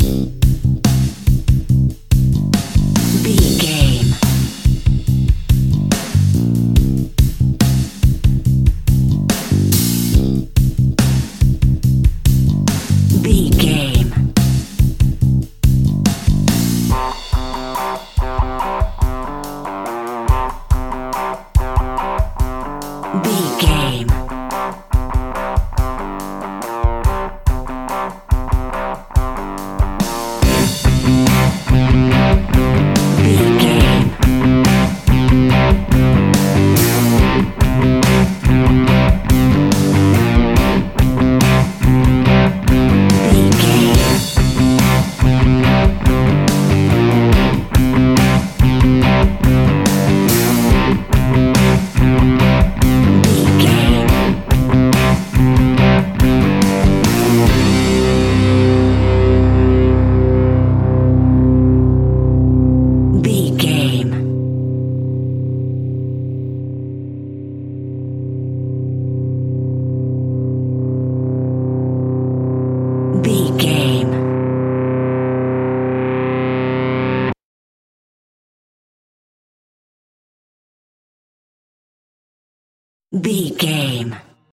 Aeolian/Minor
energetic
driving
heavy
aggressive
electric guitar
bass guitar
drums
hard rock
blues rock
distortion
rock instrumentals
distorted guitars
hammond organ